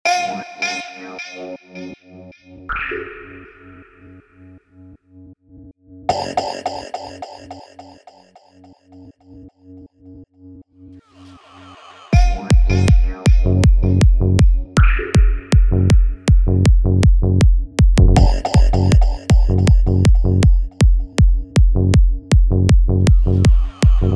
לא יודע למה אבל הבאס מפריע לי הוא מידיי חד